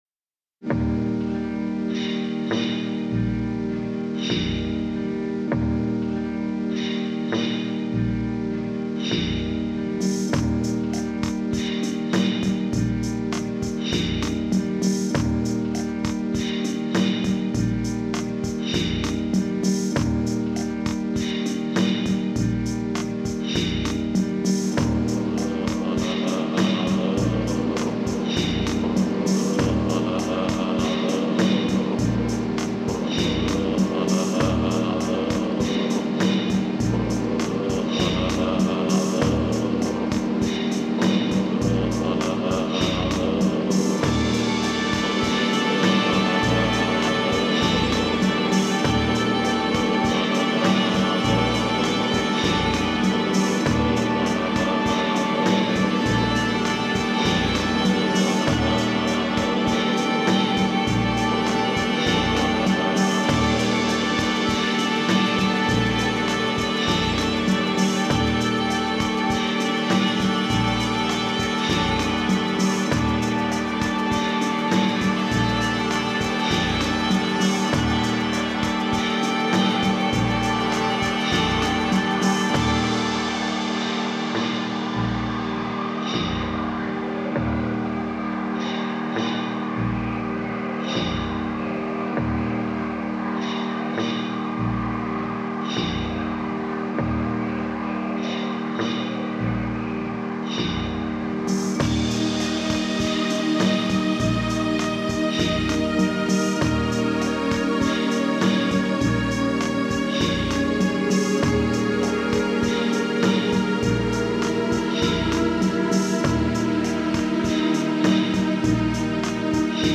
hypnotic and slighly danceable tunes
Nostalgic yet extremely straightforward stuff.